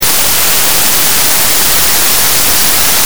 At the creek, she moves very close to the rocks and branches channeling the flow of water, making apparent the changing rhythms, pitches, and timbres that these structures produce.
Here, the water is moving more slowly and gurgling around some small branches and stones. It is less noisy and more melodious. You can also hear the air-conditioning from the Conservatory at the top of the hill. This sound seems louder in this part of the park.
flatcreek.mp3